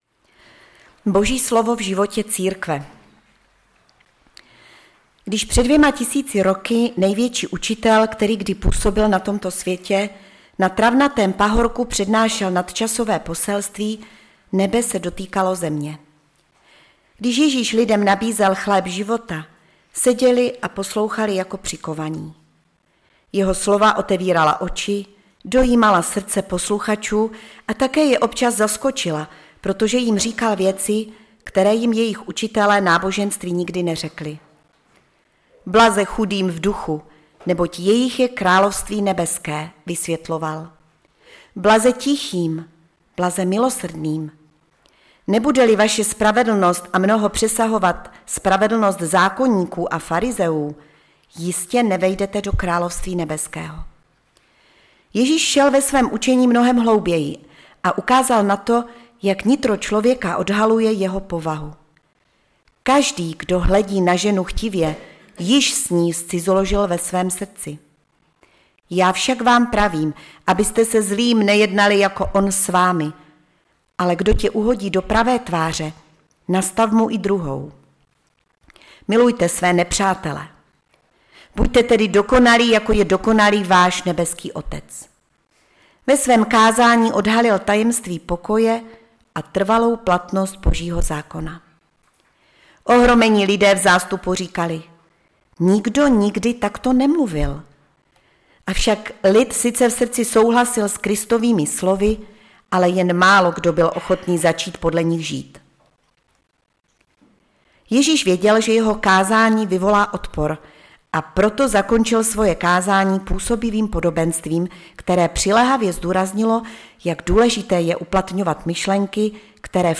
Sbor Ostrava-Radvanice. Shrnutí přednášky začíná v čase 11:15.